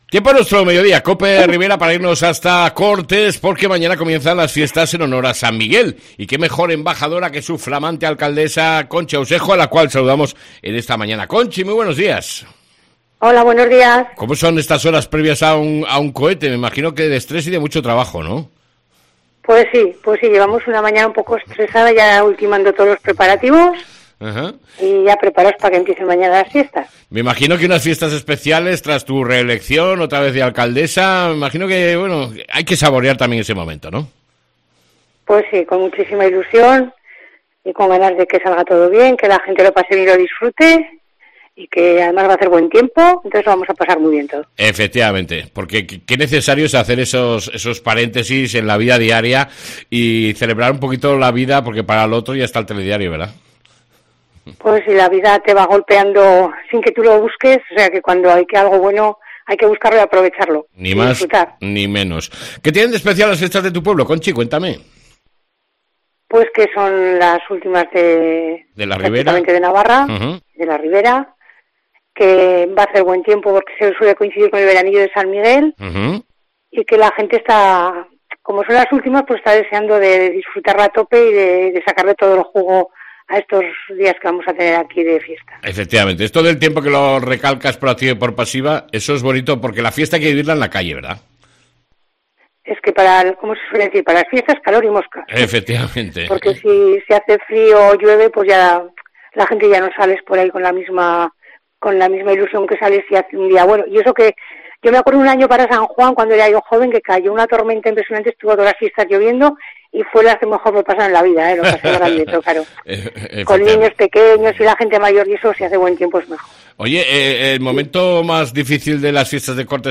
ENTREVISTA CON LA ALCALDESA DE CORTES , CONCHI AUSEJO